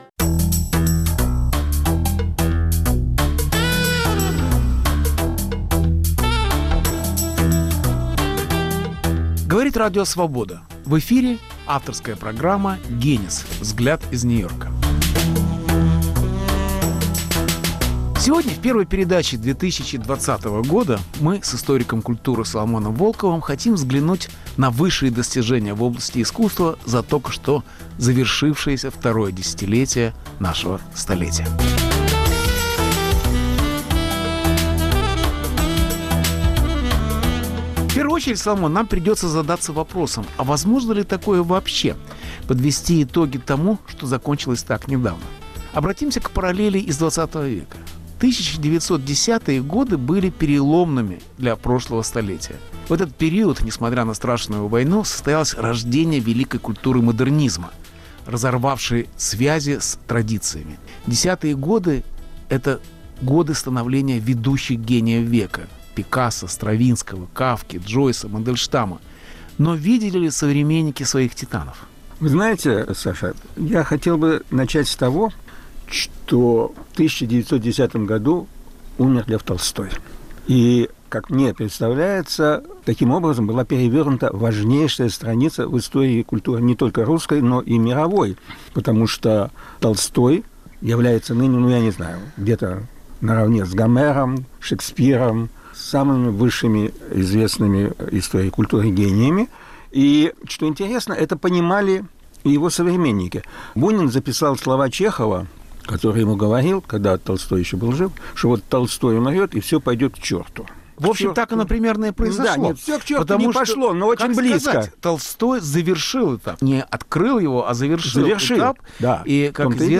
Прощание с десятыми Беседа с Соломоном Волковым о второй декаде столетия